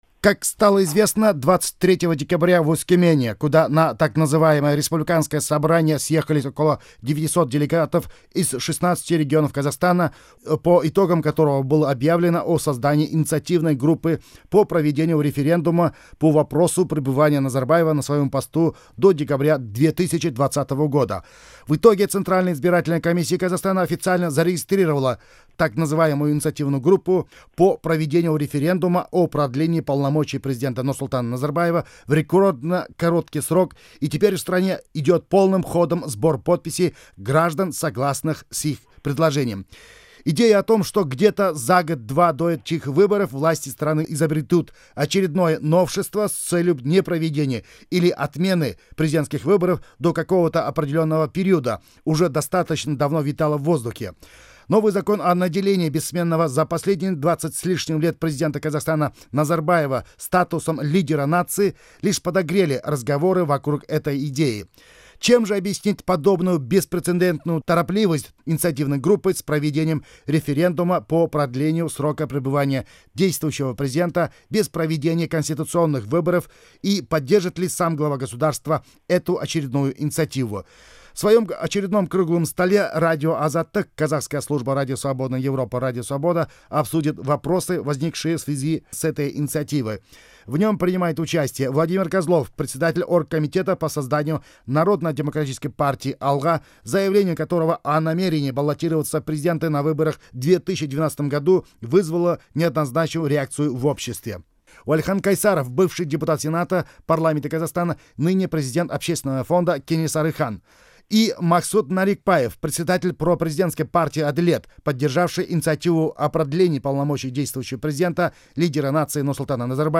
Азаттық радиосы өзінің орыс тілінде өткізген дөңгелек үстелінде осындай өзекті тақырыпқа саралайды.